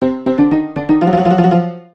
8bit_piano_kill_vo_01.ogg